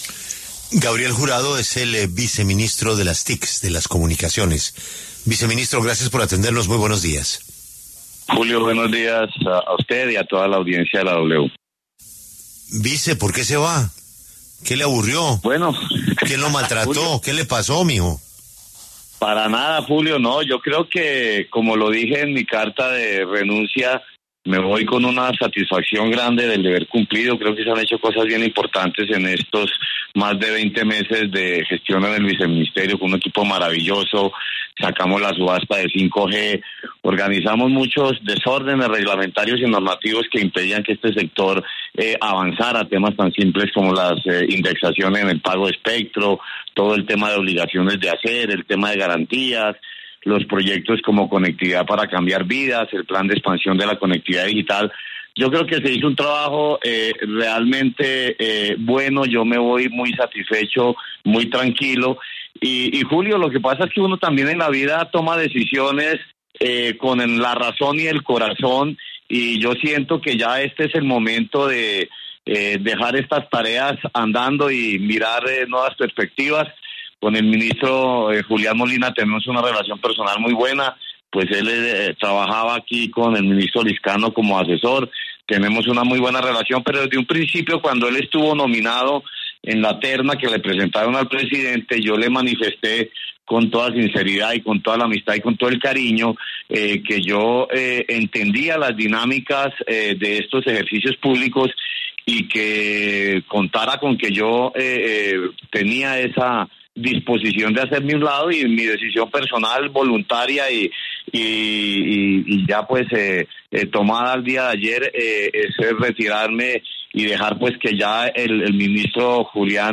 Gabriel Jurado habló en La W sobre su renuncia al cargo de viceministro de Conectividad del Ministerio TIC.